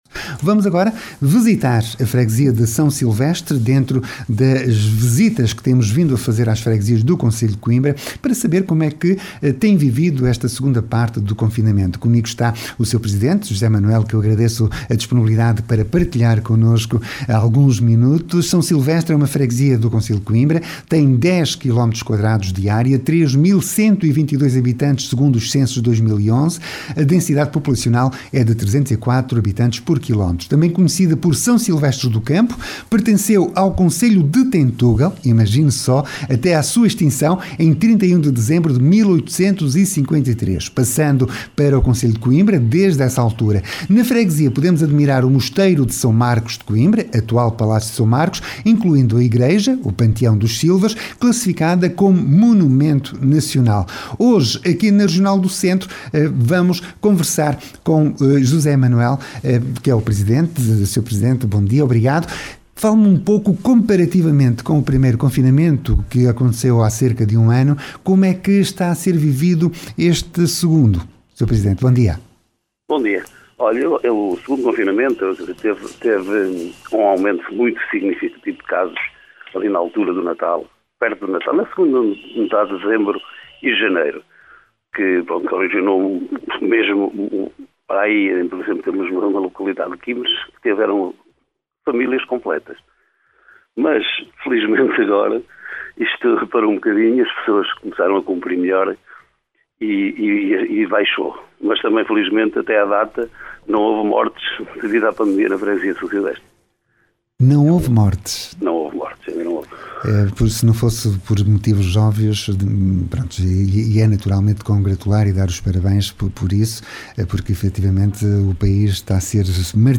Hoje visitámos a Freguesia de S. Silvestre que, pela voz do seu presidente José Manuel, ficámos a saber como está a ser vivido este segundo confinamento.